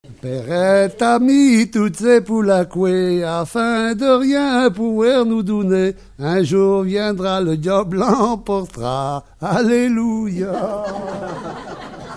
Genre brève
Enquête Arexcpo en Vendée-C.C. Saint-Fulgent
Catégorie Pièce musicale inédite